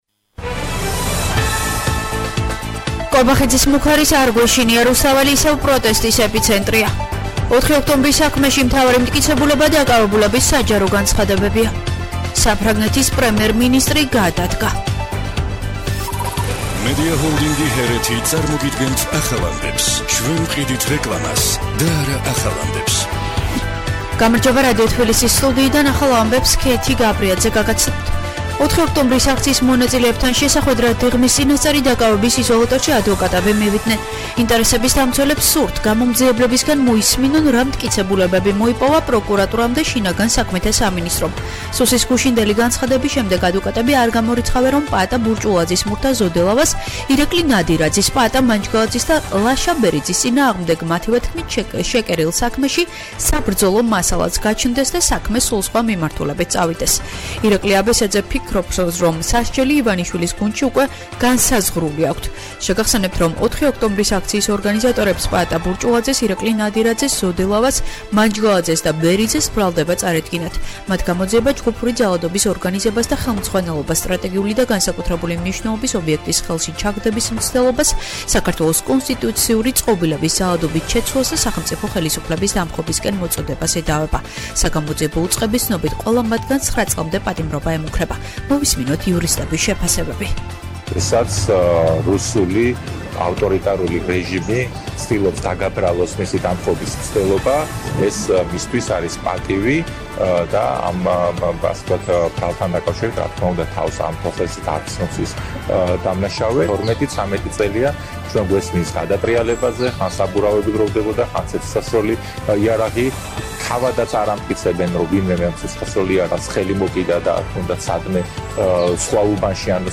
ახალი ამბები 13:00 საათზე